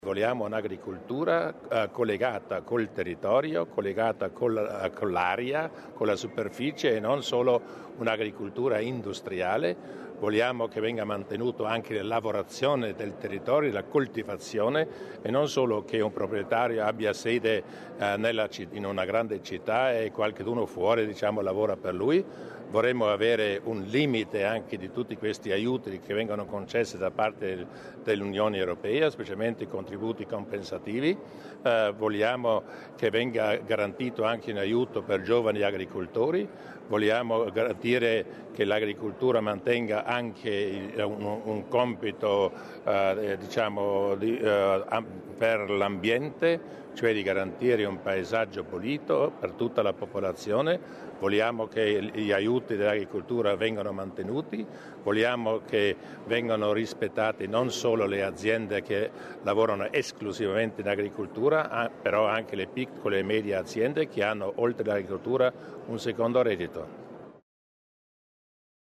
Il Presidente Durnwalder sulle priorità a livello locale